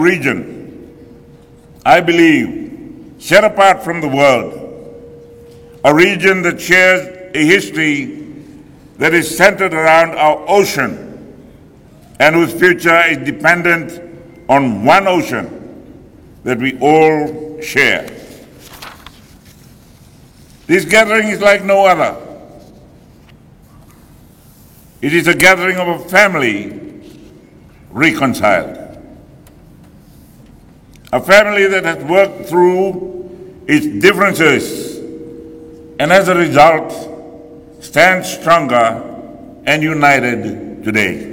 In his opening address at the 2023 Pacific Islands Forum Special Leaders meeting in Nadi, Rabuka emphasized the importance of preserving traditional practices such as the matanigasau ceremony, which is Fiji’s age-old ceremony of rebuilding relationships by seeking forgiveness for wrongs committed.